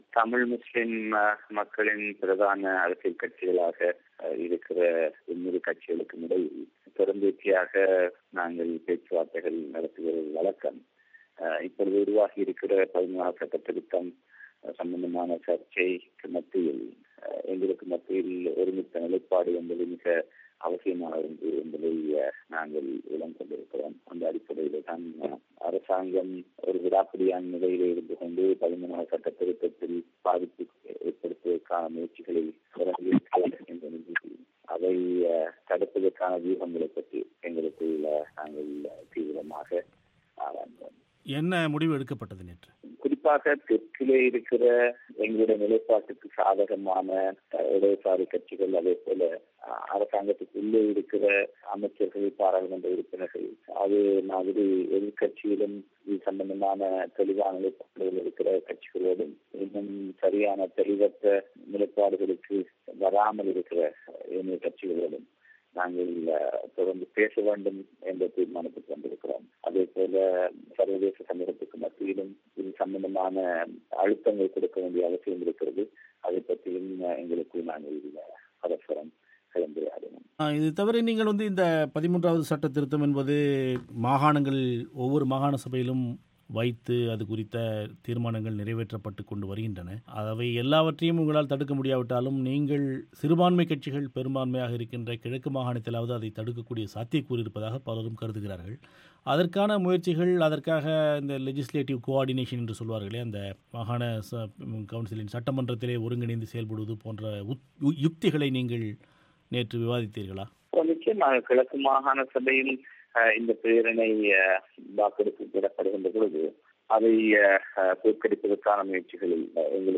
ரவூப் ஹக்கீம் செவ்வி